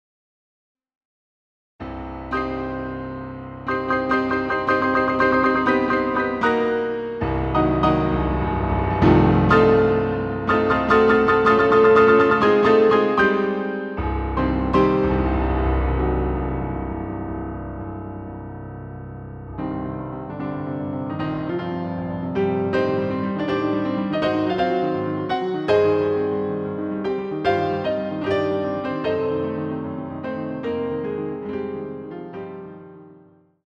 using the stereo sa1mpled sound of a Yamaha Grand Piano.